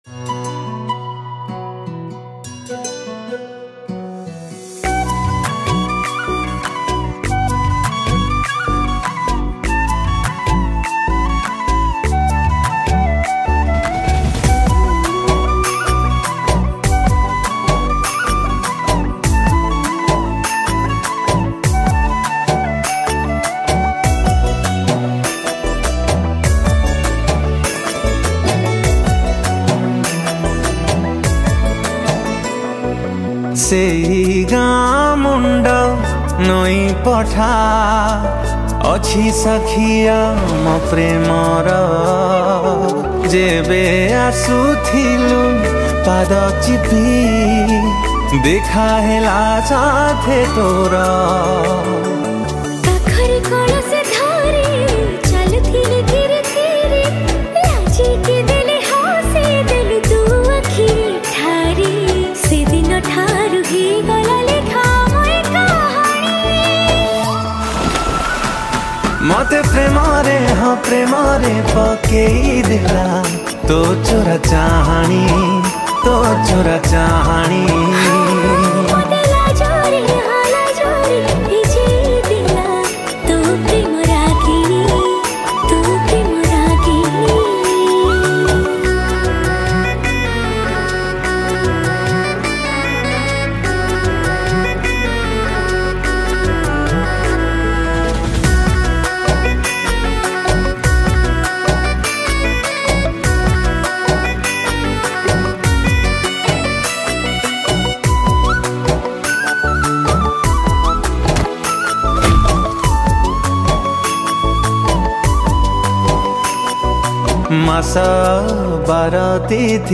KEYBOARD & RHYTHM
FLUTE